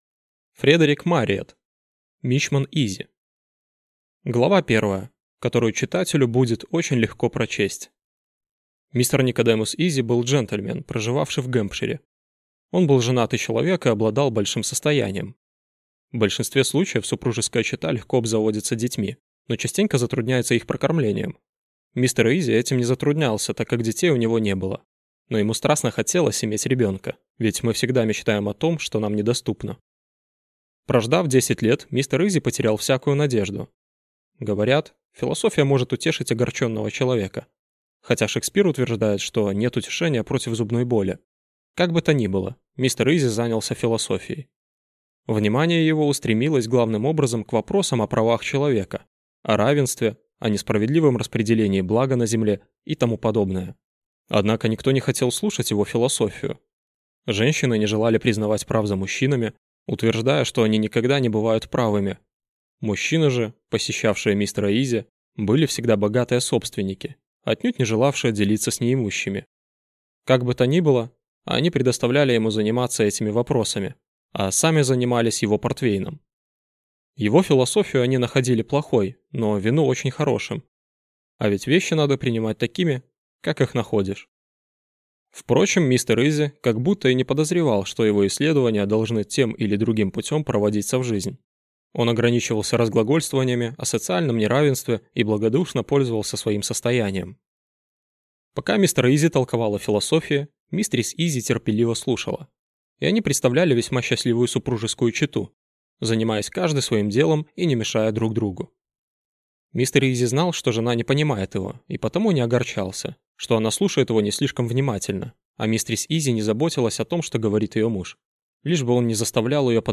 Аудиокнига Мичман Изи | Библиотека аудиокниг
Прослушать и бесплатно скачать фрагмент аудиокниги